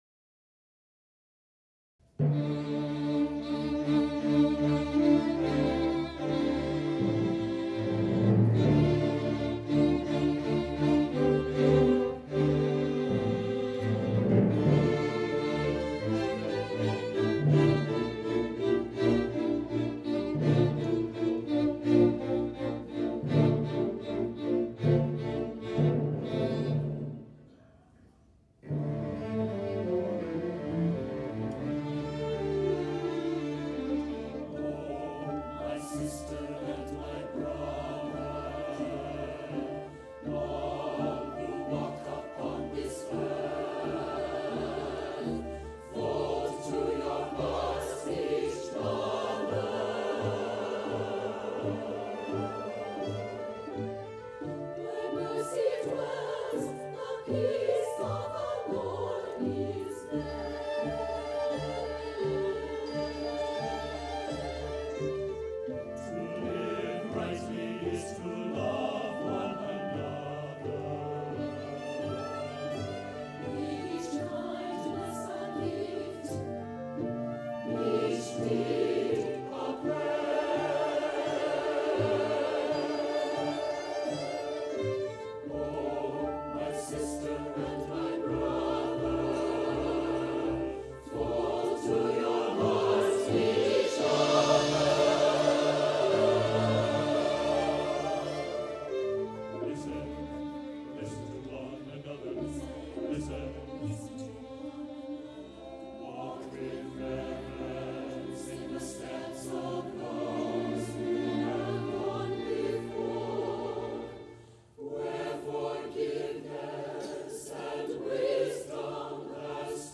for SATB Chorus and Chamber Orchestra (2004-07)
The work opens with accented chords in the strings, clarinet, bassoon, and timpani.
The tender aspects are introduced in the arpeggio patterns in the piano.
Passages of tenderness alternate and intertwine with passages of strength to form a confluence of the message -- a prayer for peace.